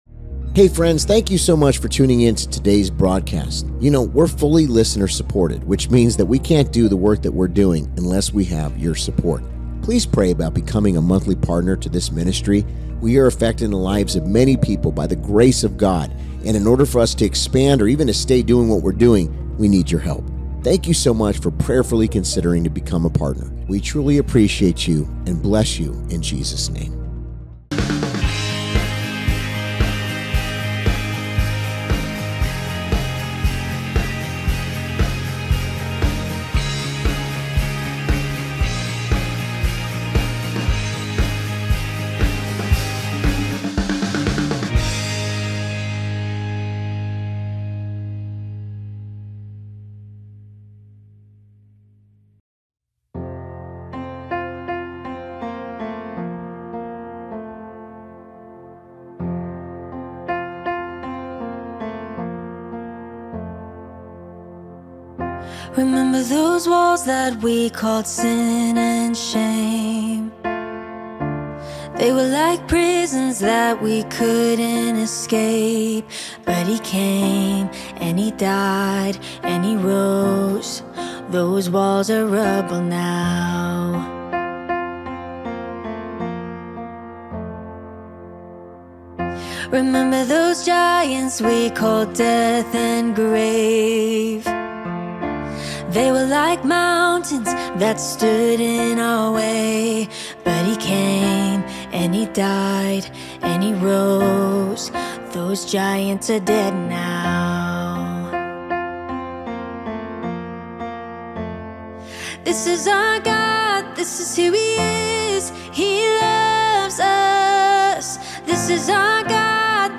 🙏 Sunday Service • Roman’s Road: The Path of Salvation 🙏